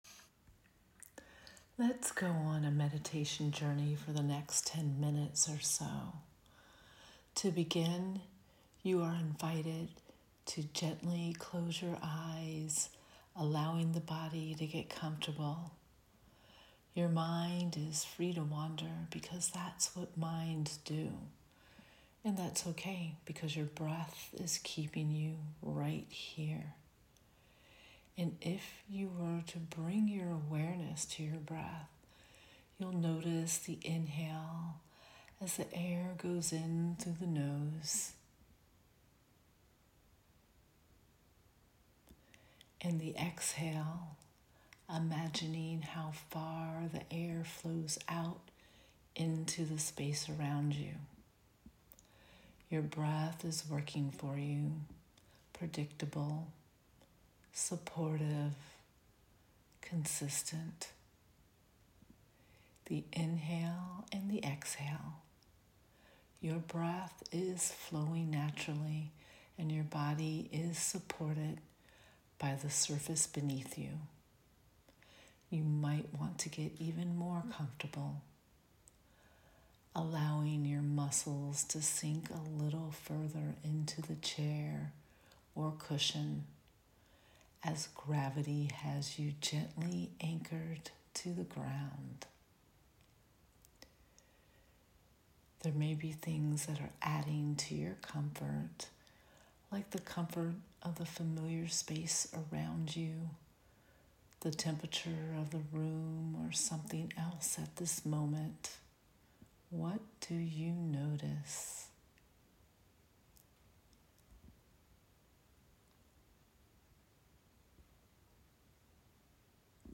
BONUS: Guided Meditation